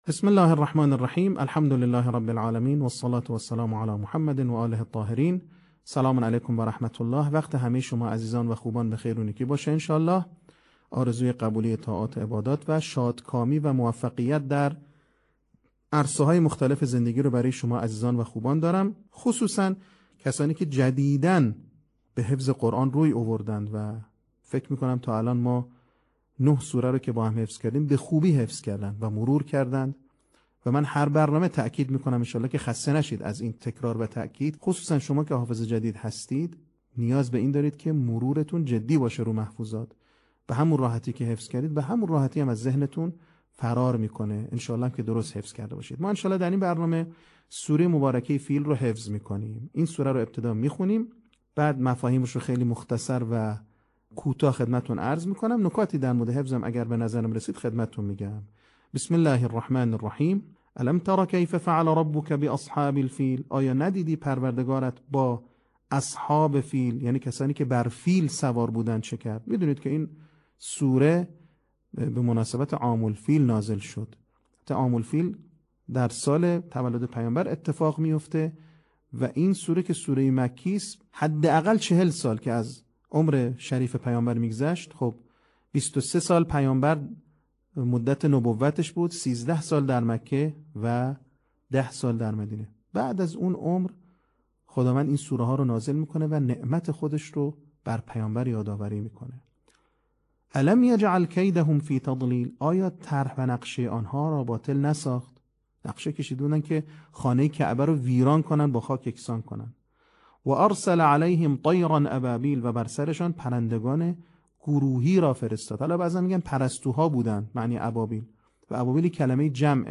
صوت | آموزش حفظ سوره فیل
آموزش قرآن